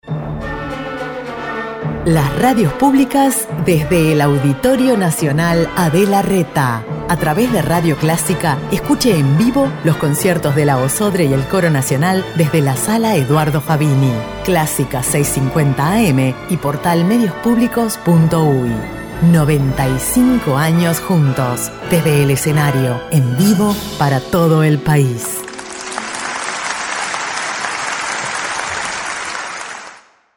Trasmisiones-Clasica-PROMO-Desde-01-06.mp3